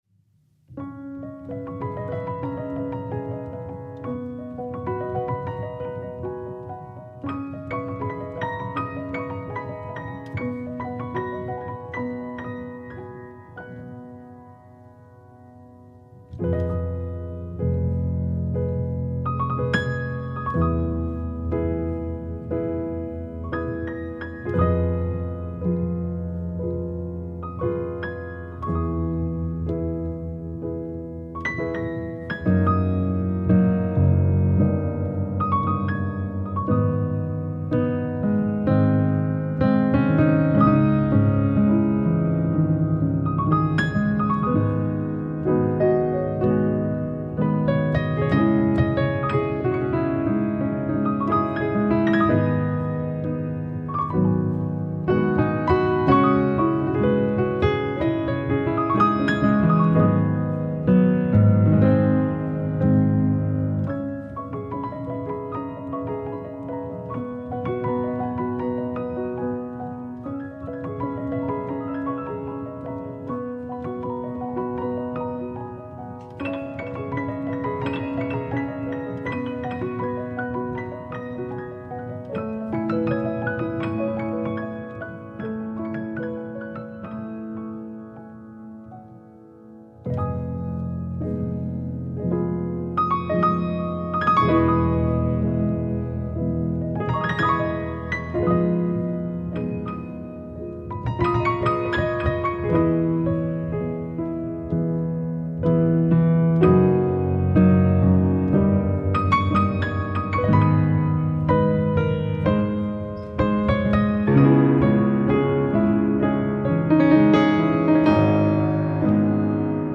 vibrant new track